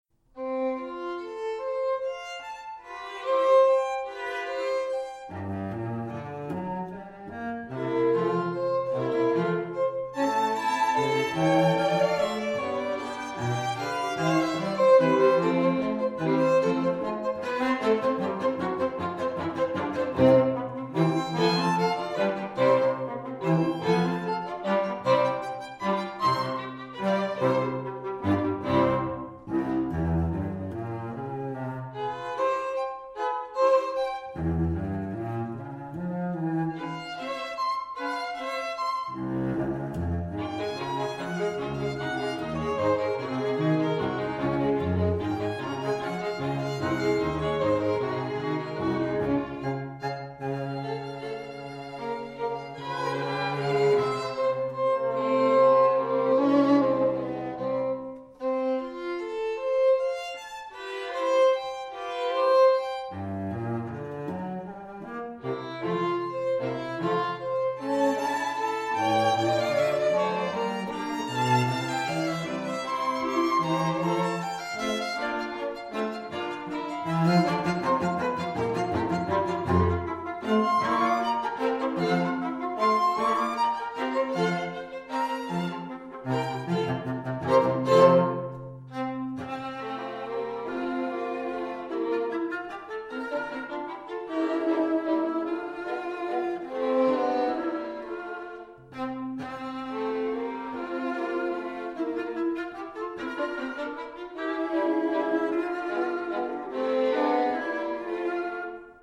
Soundbite 3rd Movt
The third movement is a Haydnesque Menuetto, very Viennese.